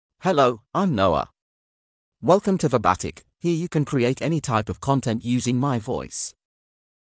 MaleEnglish (United Kingdom)
NoahMale English AI voice
Noah is a male AI voice for English (United Kingdom).
Voice sample
Listen to Noah's male English voice.
Noah delivers clear pronunciation with authentic United Kingdom English intonation, making your content sound professionally produced.